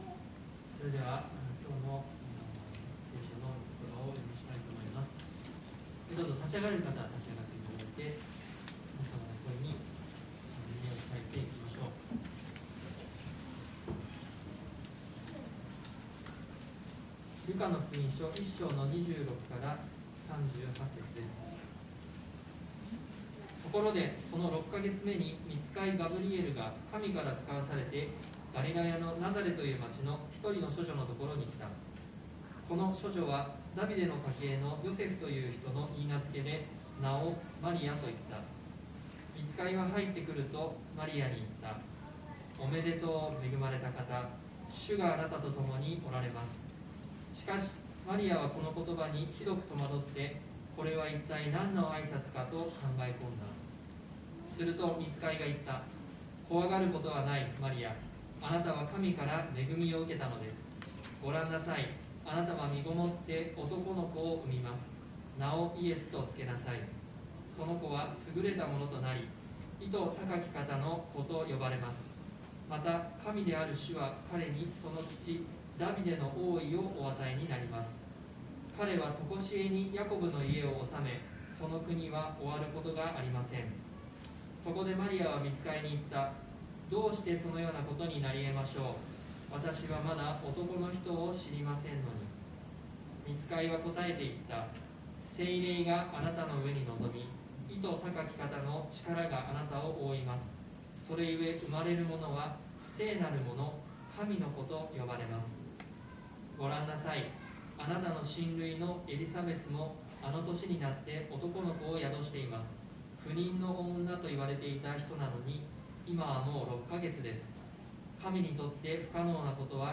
TOP > 礼拝メッセージ(説教) > キリストが住まわれる キリストが住まわれる 2021 年 12 月 19 日 礼拝メッセージ(説教